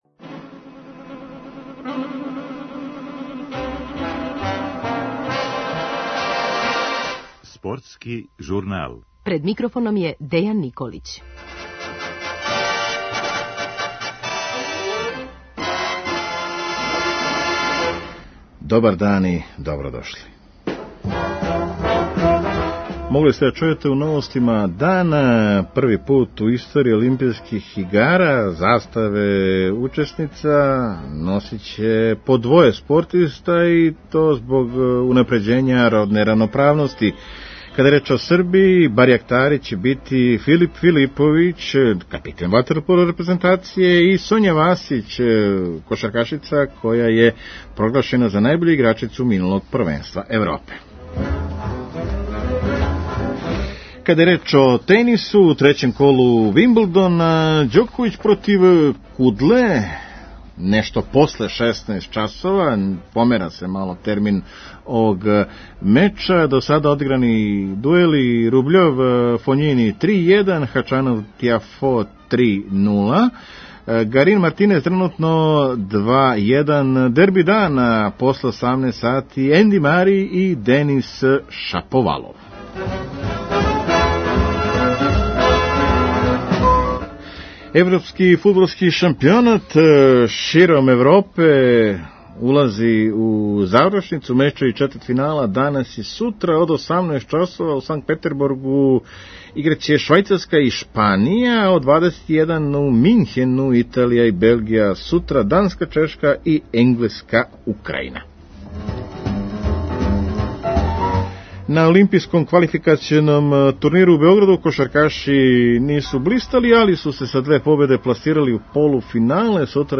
Вечерас су на програму прва два сусрета четвртфинала европског фудбалског првенства Швајцарска-Шпанија и Белгија-Италија. Тим поводом гост је прослављени Иван Голац.